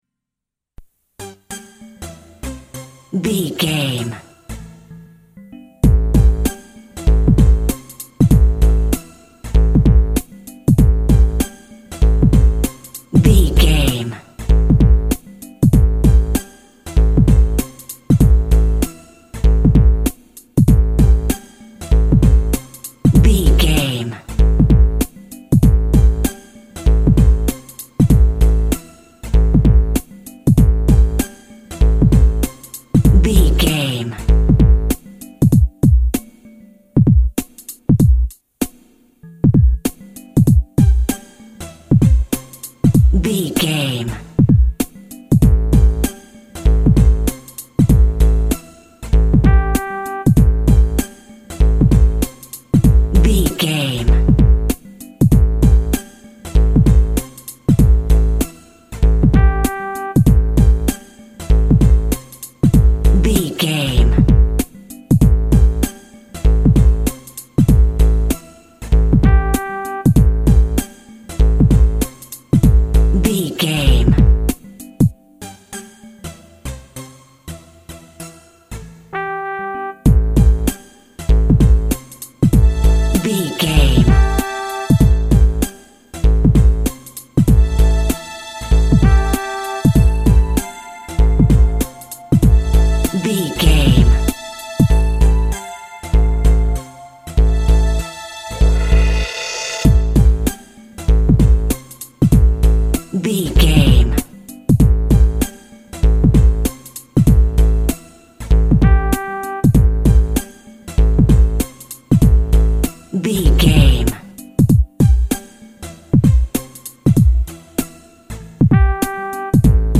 Aeolian/Minor
hip hop
hip hop music
synth lead
synth bass
hip hop synths